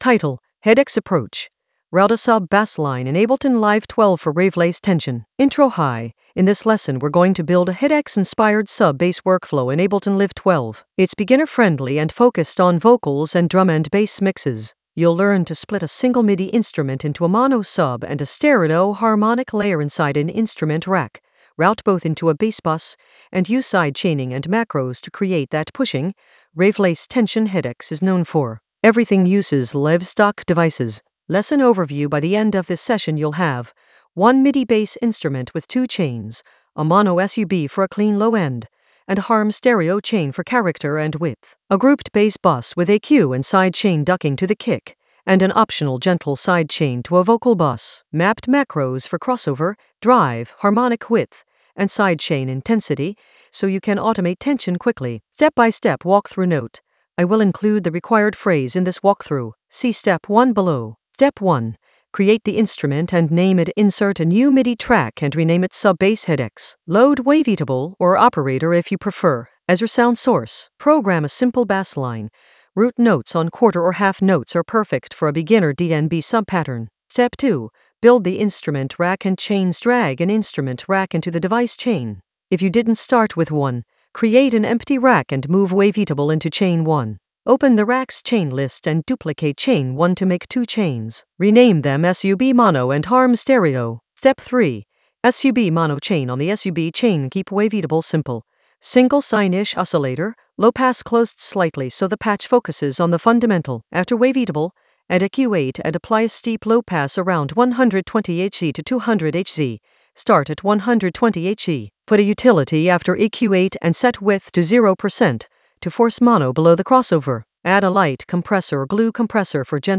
Narrated lesson audio
The voice track includes the tutorial plus extra teacher commentary.